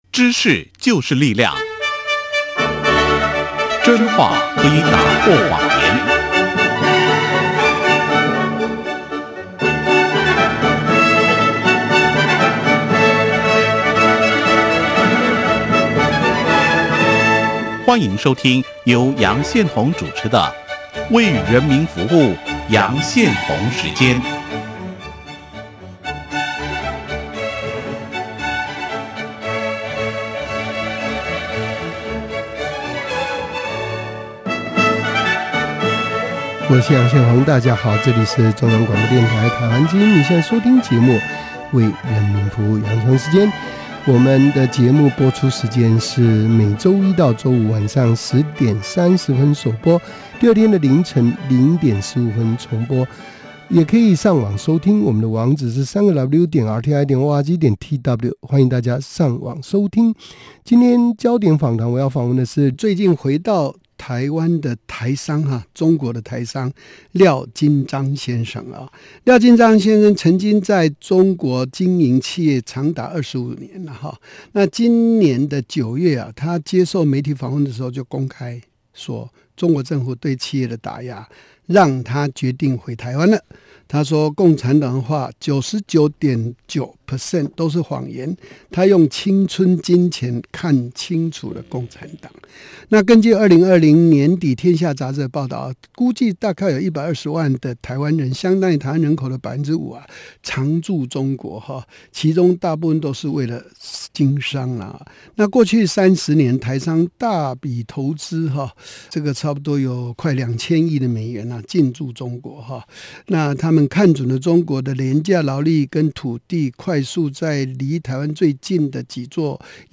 專訪